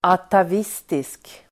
Ladda ner uttalet
Uttal: [atav'is:tisk]
atavistisk.mp3